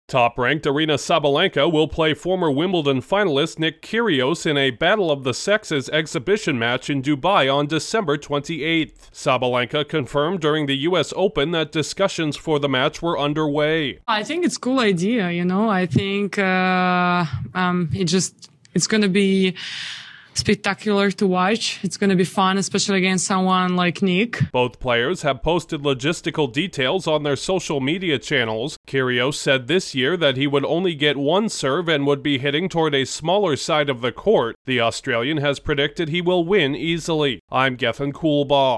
A match made famous by tennis icon Billie Jean King is returning with a modern twist. Correspondent